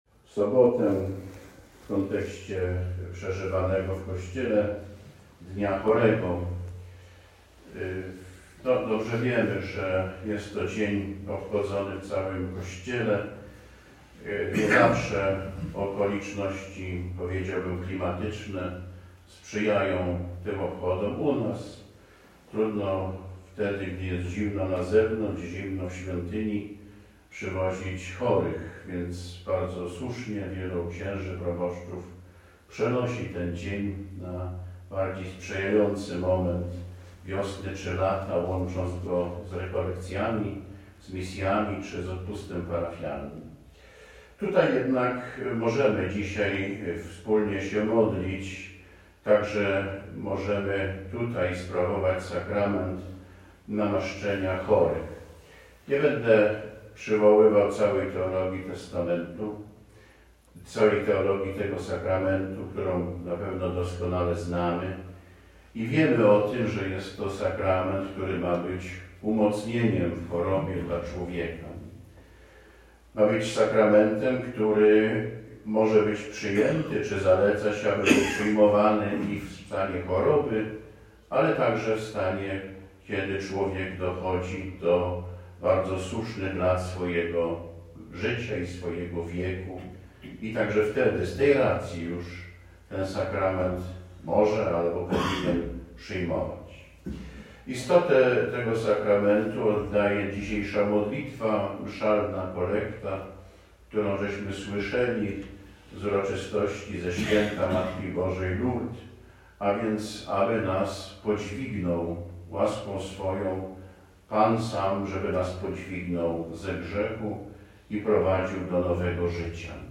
Bp Wętkowski - homilia, konferencja naukowa pro-life, WSD, 27.04.2024